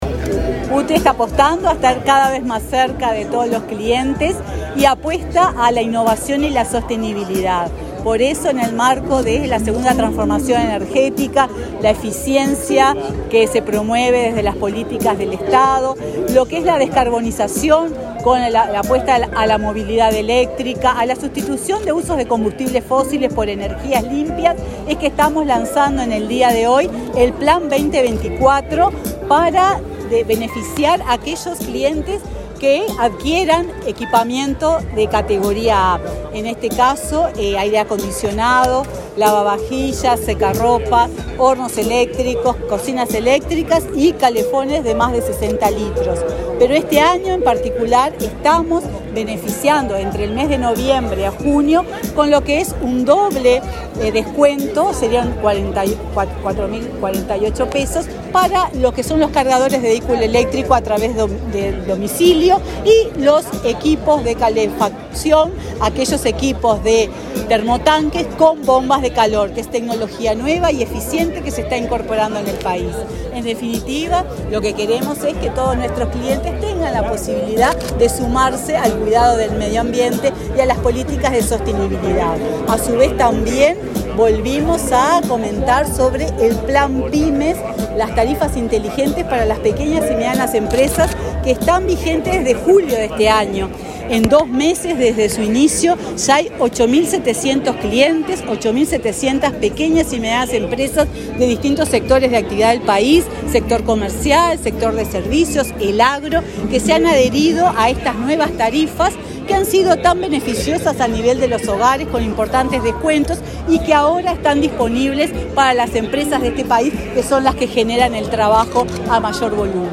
Declaraciones de la presidenta de UTE, Silvia Emaldi
Este martes 12, la presidenta de la UTE, Silvia Emaldi, dialogó con la prensa, luego de inaugurar el stand de ese organismo en la Expo Prado.